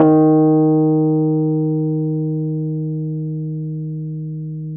RHODES-EB2.wav